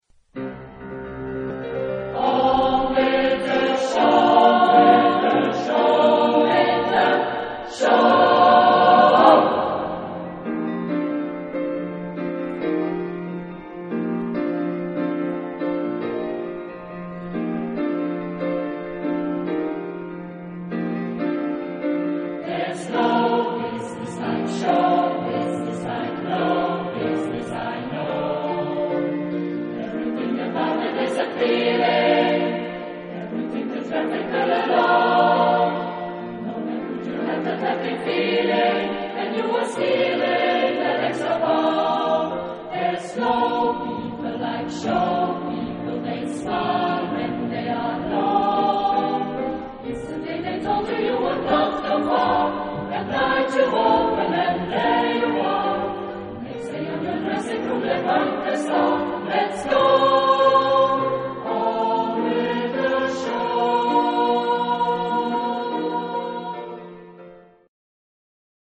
Type de choeur : SSA  (3 voix égales de femmes )